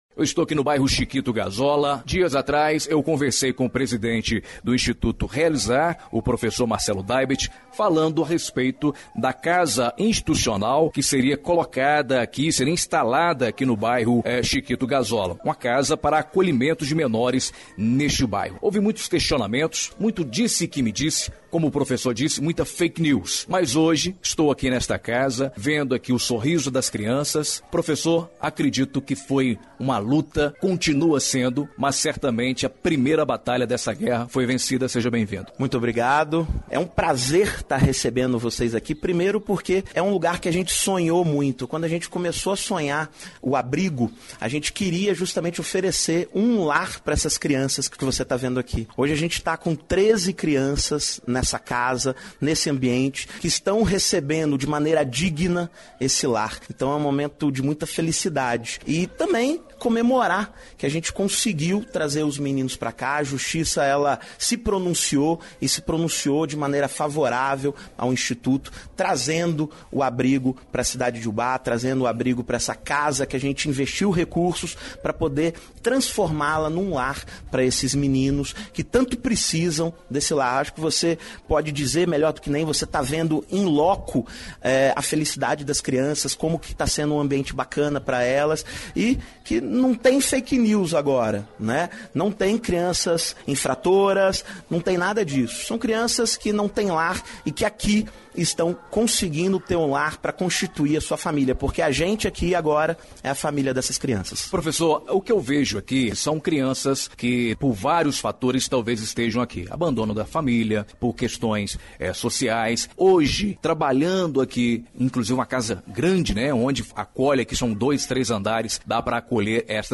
Entrevista exibida na Rádio Educadora AM/FM – Ubá – MG